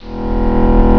charge.wav